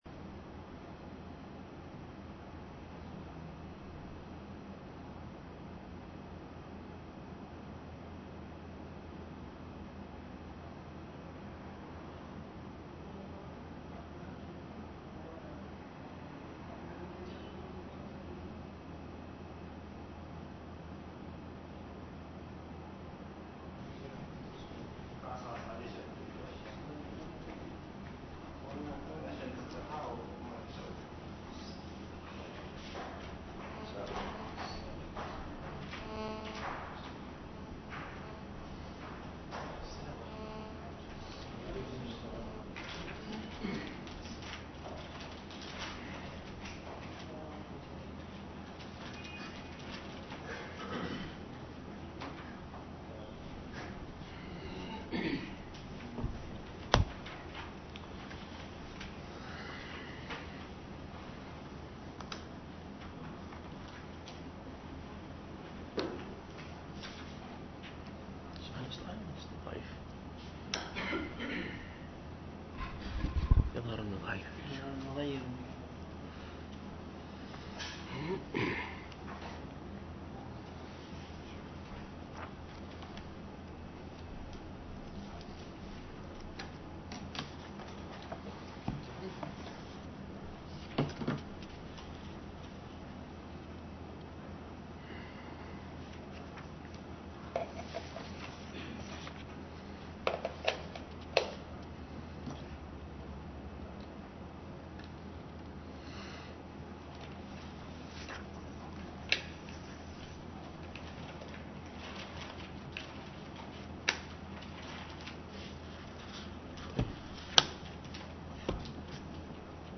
04-مقدمة التفسير للشيخ ابن قاسم رحمه الله – الدرس الرابع